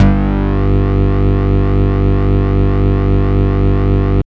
29 BASS   -L.wav